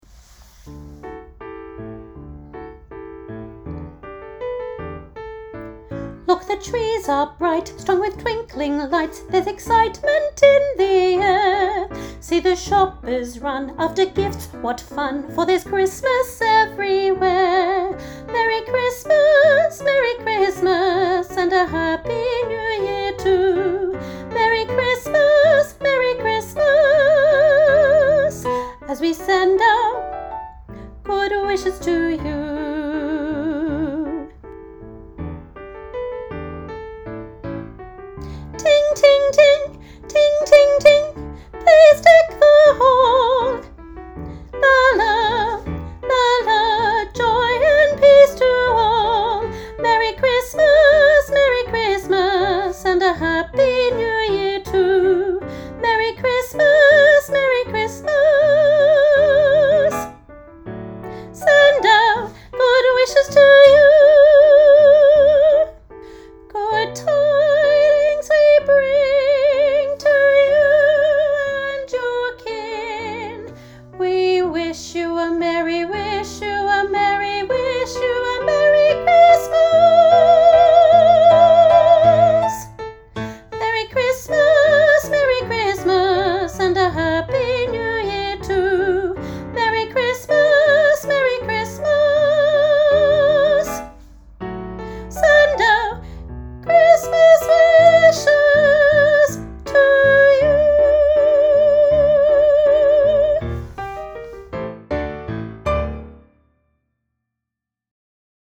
Elementary Choir – Christmas Wishes, Soprano – Part 1
Elementary-Choir-Christmas-Wishes-Soprano-Part-1.mp3